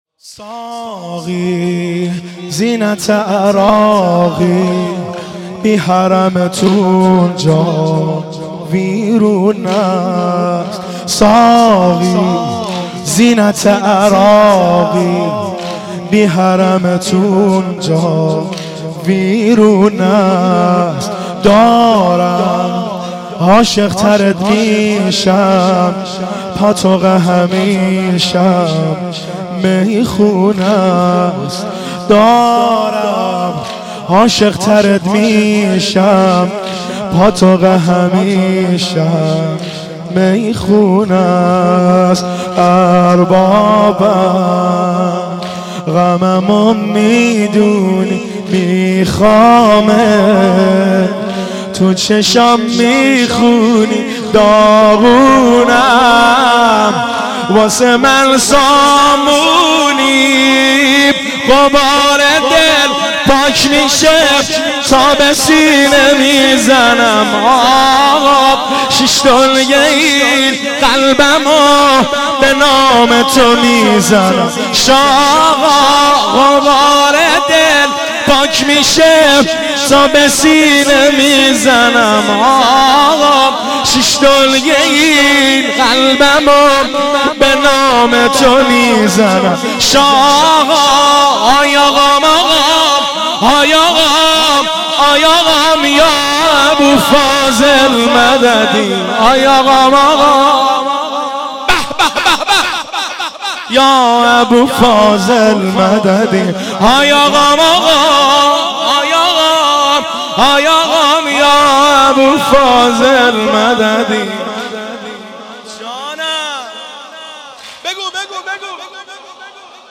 صوت مراسم شب اول محرم ۱۴۳۷ هیئت غریب مدینه امیرکلا ذیلاً می‌آید: